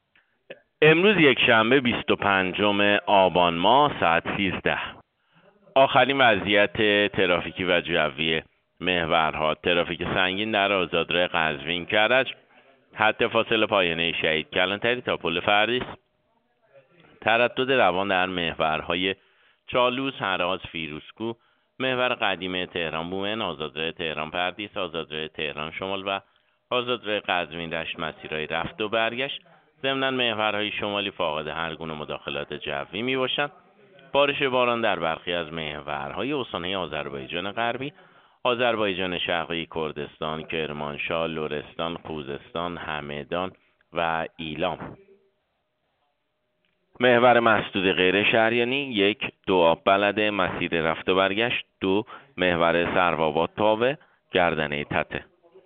گزارش رادیو اینترنتی از آخرین وضعیت ترافیکی جاده‌ها ساعت ۱۳ بیست و پنجم آبان؛